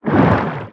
Sfx_creature_pinnacarid_push_05.ogg